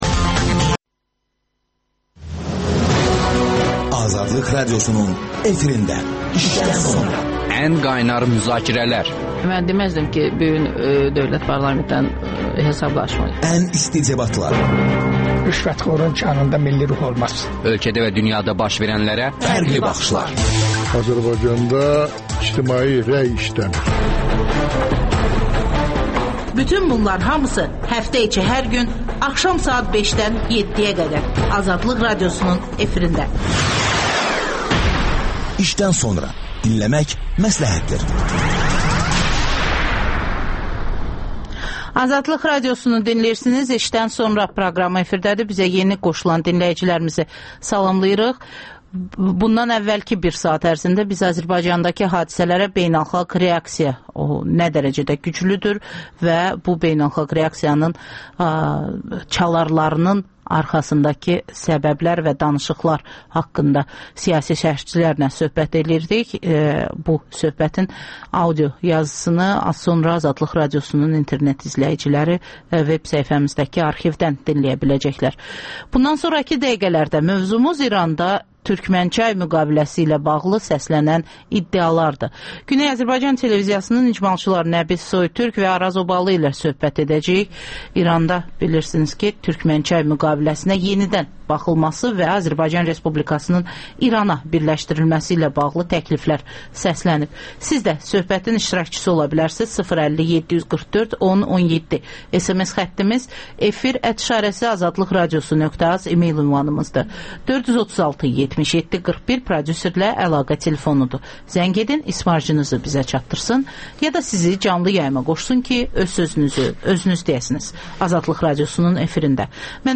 söhbət